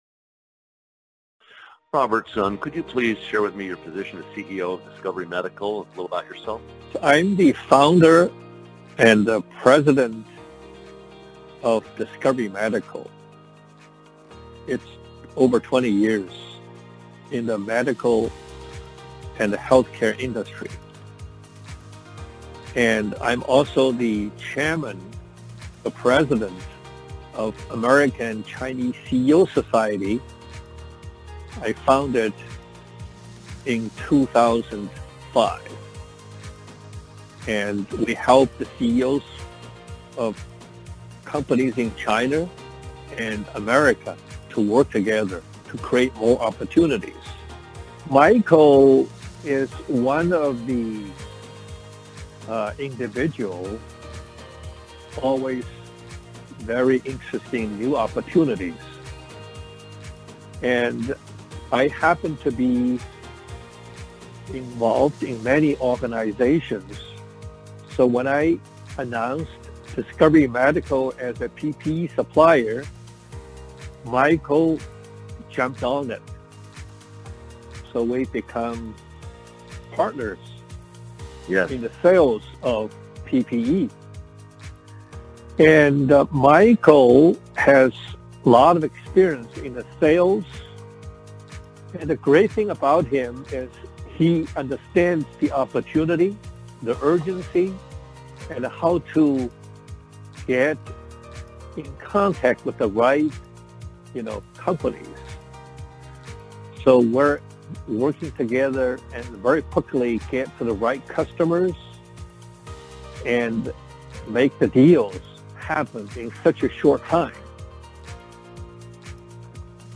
CEO’s Recorded Testimonials